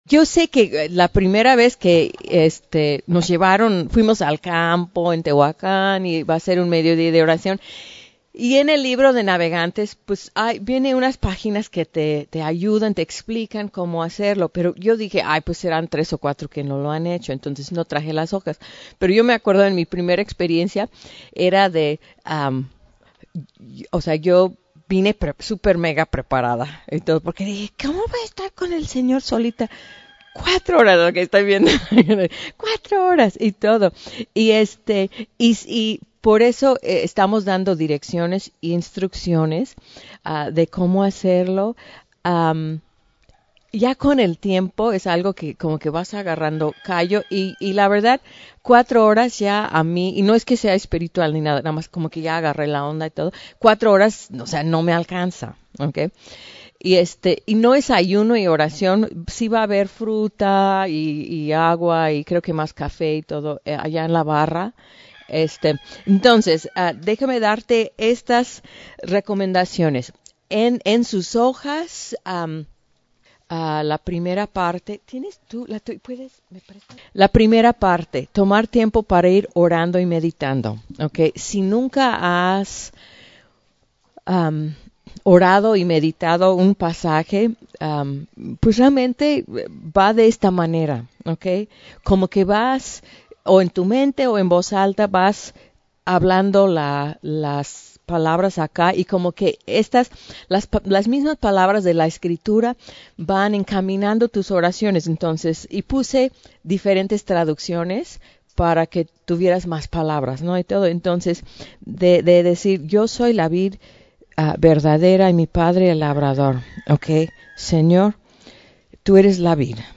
Retiro Staff 2018